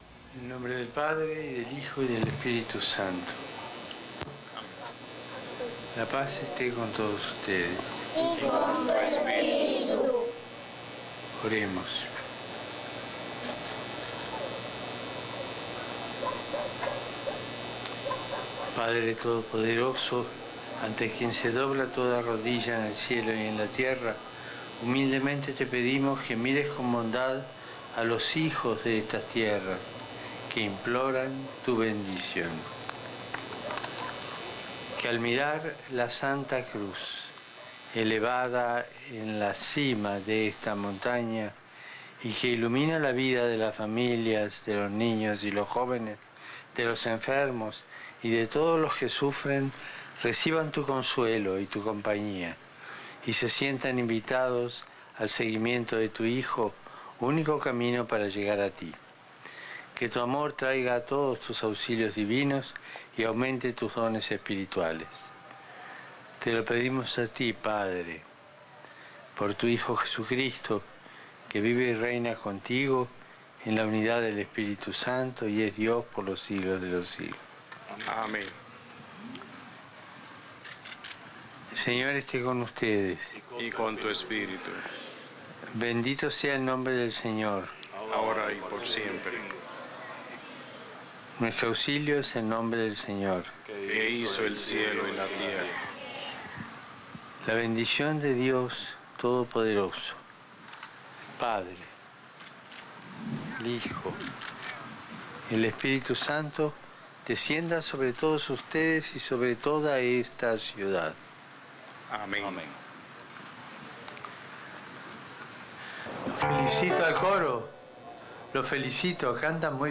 El Papa bendice a la ciudad de Holguín desde la loma de la Cruz
Bendicion-del-Papa-Francisco-a-la-Ciudad-de-Holgu--n.mp3